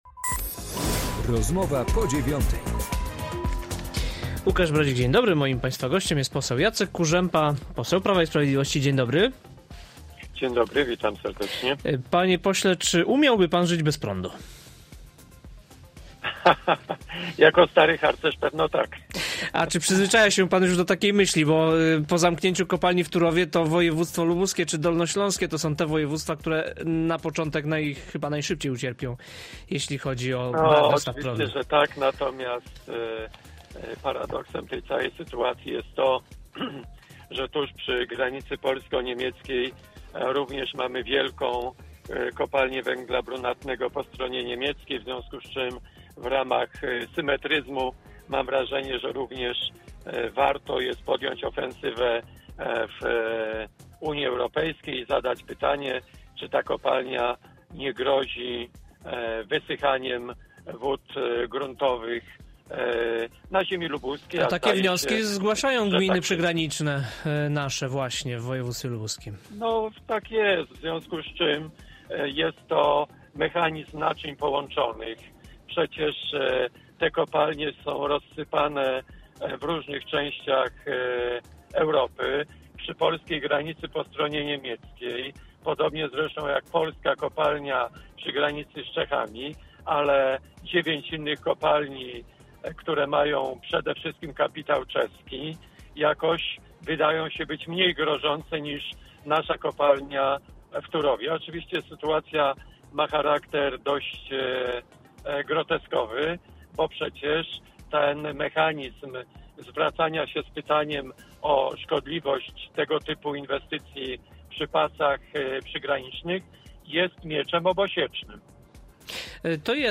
Jacek Kurzępa, lubuski poseł Prawa i Sprawiedliwości